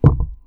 SND-thud-2.wav